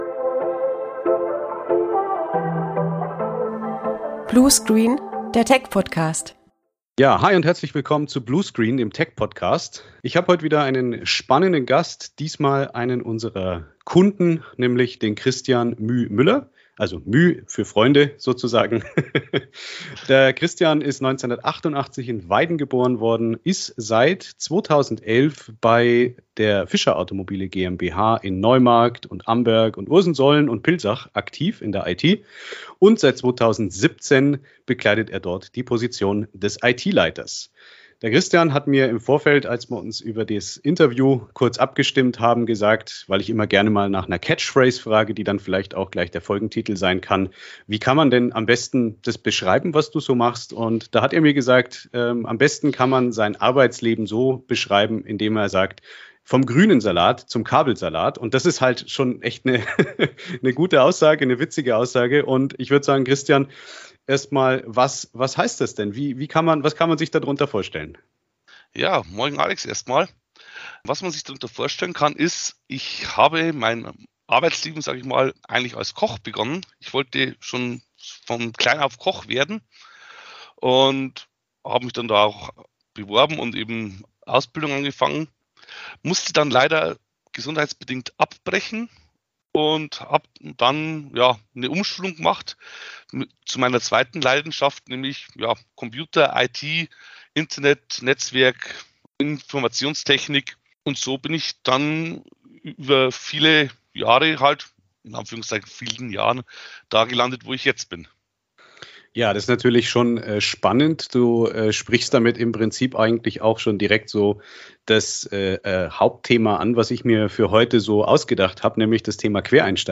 Folge 5 von Bluescreen - Der Tech-Podcast! Im Interview